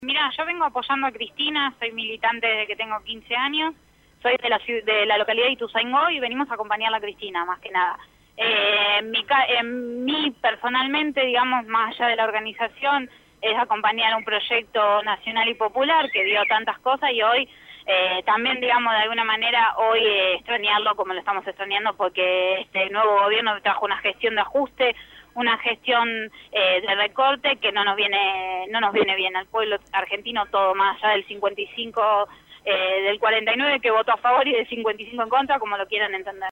(13/4/2016) Citada a indagatoria por el juez Claudio Bonadio, en la causa de dólar futuro, esta mañana Cristina Fernández de Kirchner presentó un escrito en los Tribunales de Comodoro Py, mientras que en las afueras de la sede judicial una multitud proveniente de distintos puntos del país se congregó para brindar su apoyo a la ex mandataria.